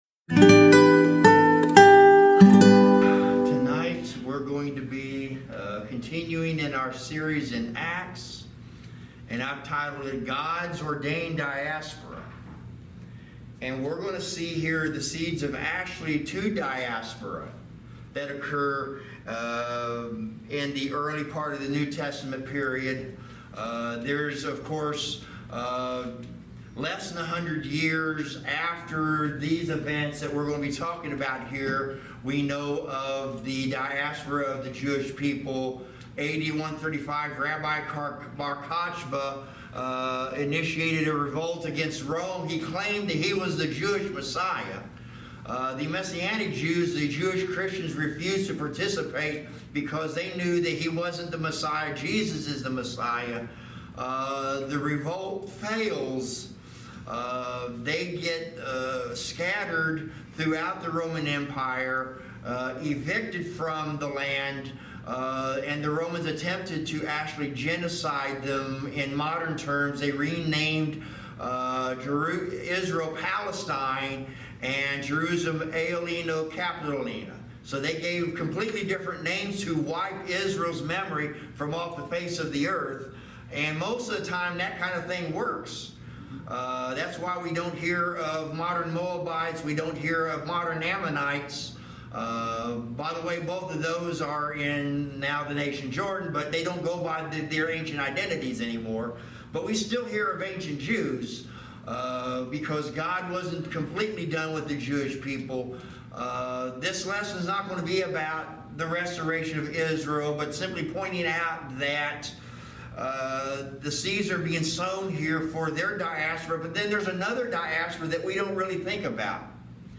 Thirsty Thursday Midweek Teaching